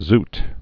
(zt)